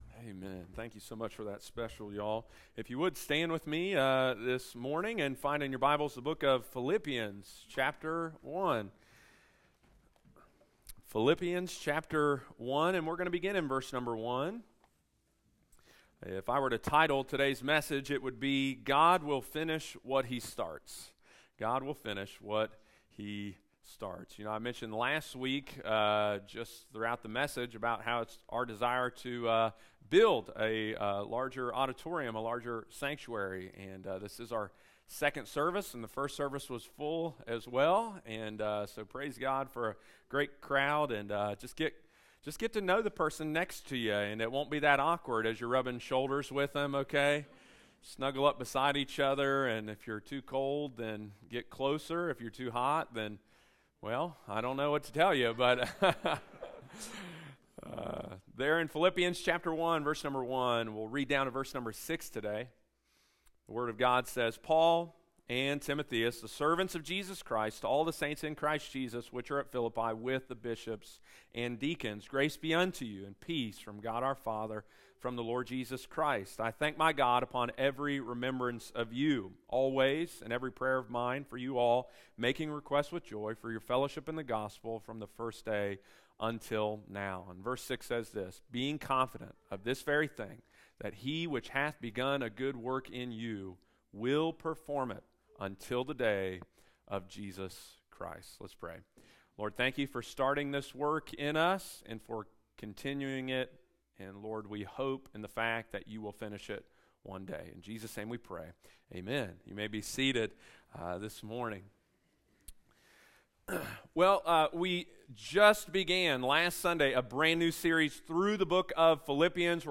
– Philippians Sermon Series – Lighthouse Baptist Church, Circleville Ohio